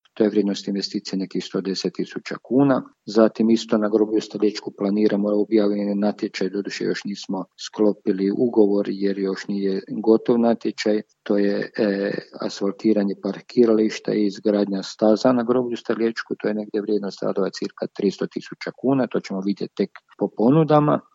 Više o tome, načelnik Stjepan Ivoš